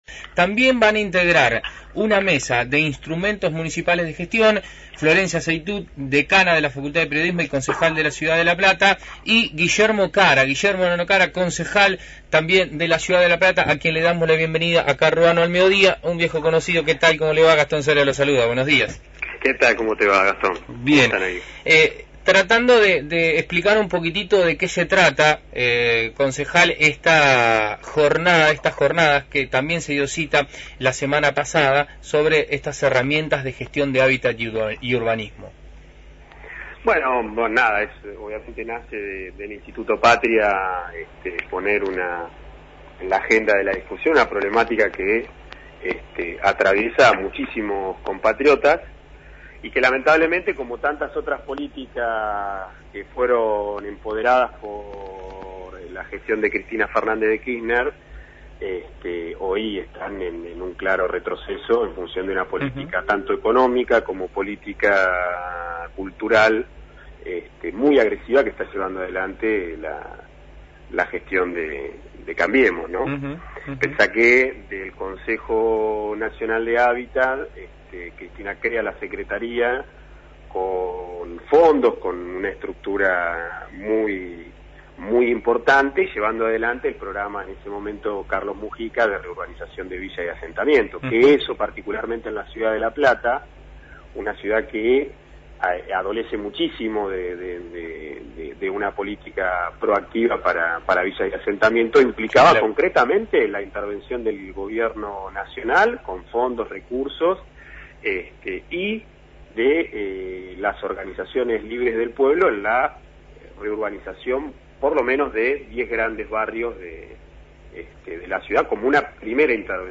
Entrevista a Guillermo Cara (Concejal La Plata)  2ª Jornada sobre Herramientas de Gestión de Hábitat y Urbanismo en el Instituto PATRIA.